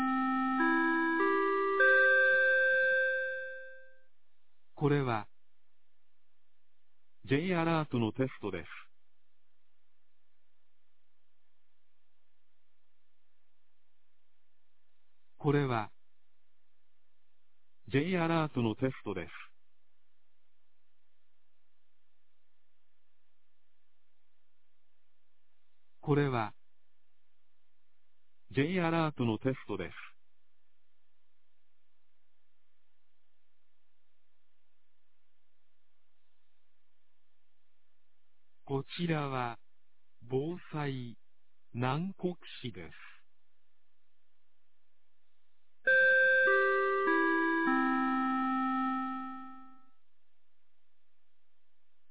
2022年02月16日 11時01分に、南国市より放送がありました。